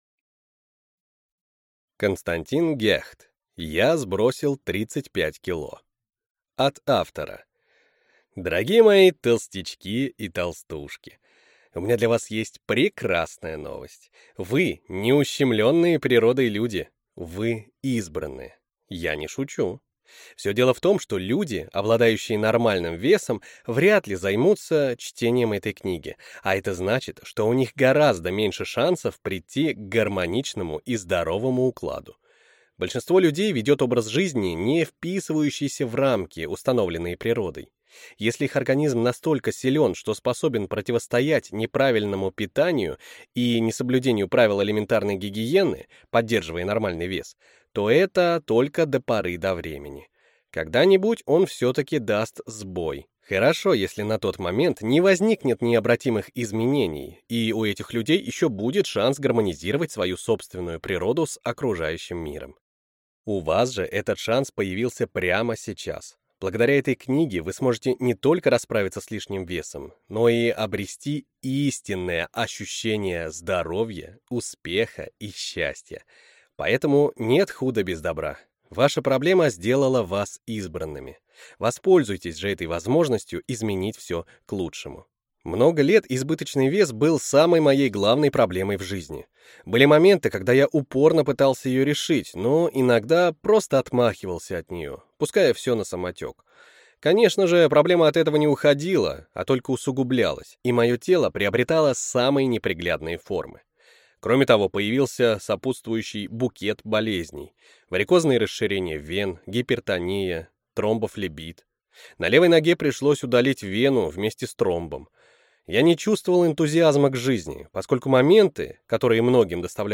Прослушать и бесплатно скачать фрагмент аудиокниги